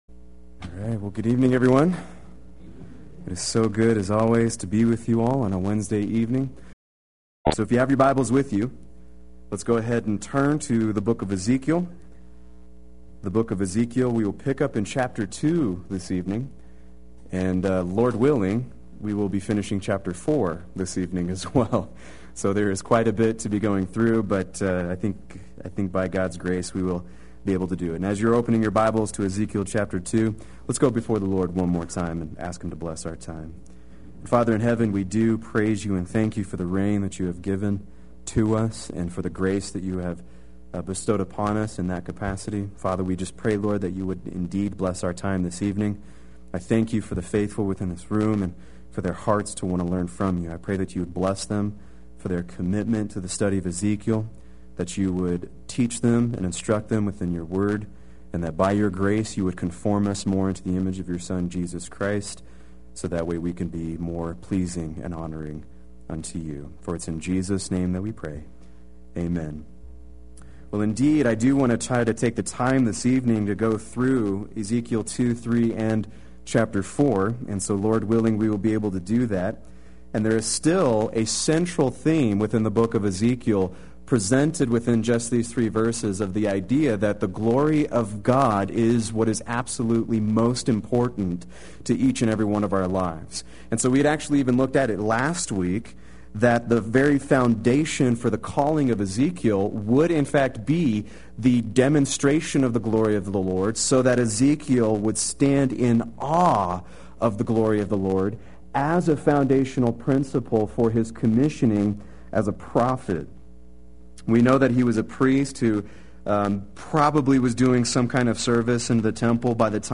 Play Sermon Get HCF Teaching Automatically.
Chapters 2-4 Wednesday Worship